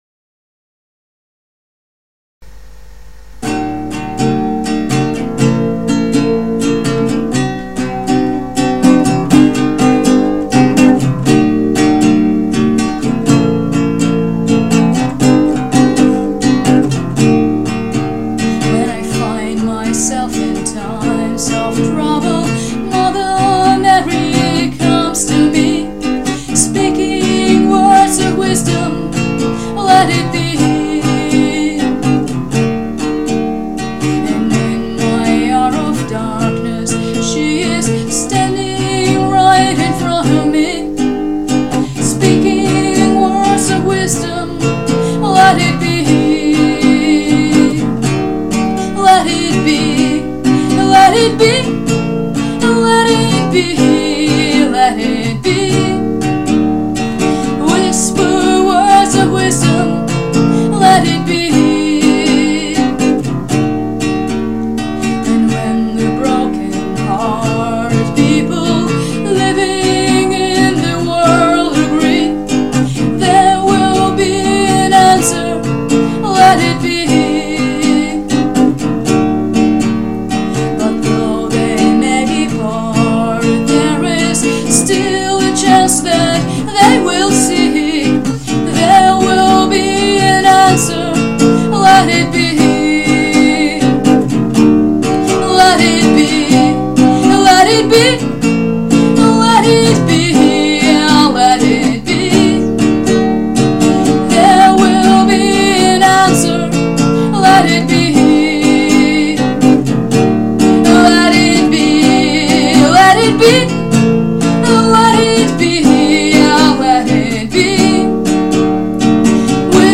((cover, request))